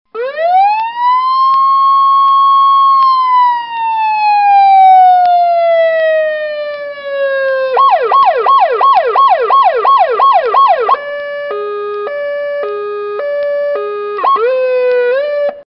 Звук сирены полицейской лодки или береговой охраны